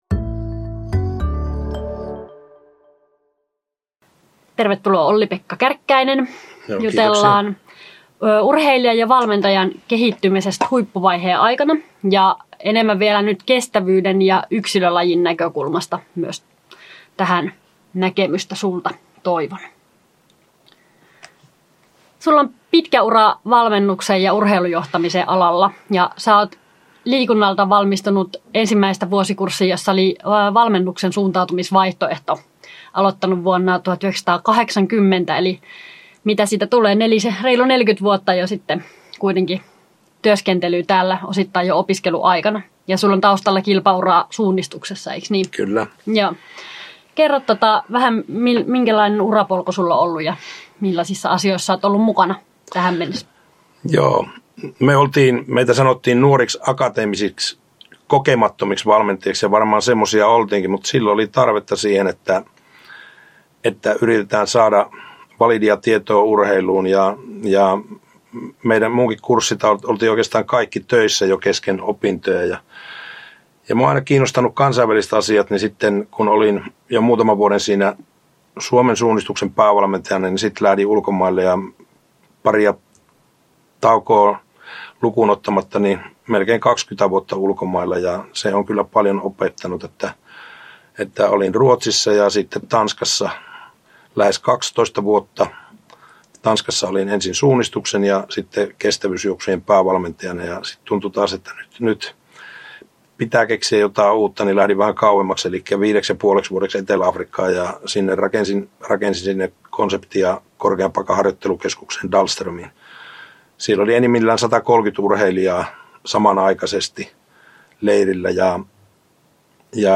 haastattelu